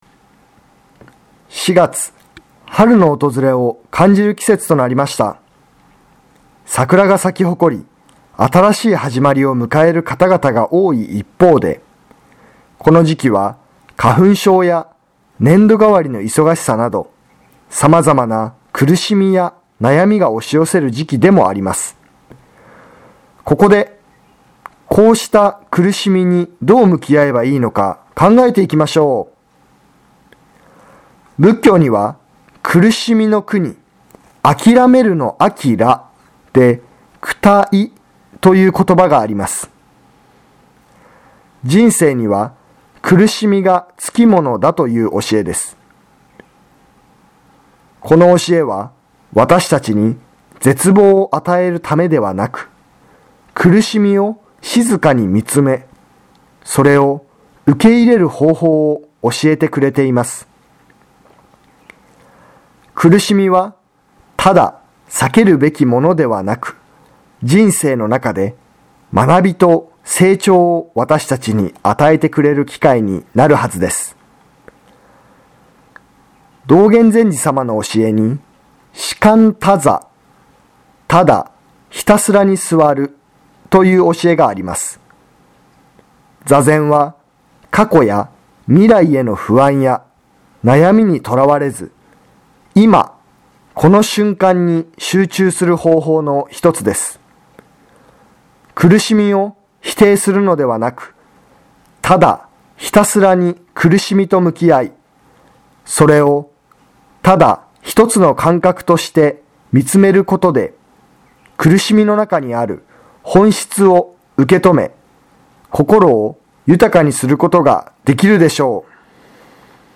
テレフォン法話
曹洞宗岐阜宗務所では電話による法話の発信を行っています。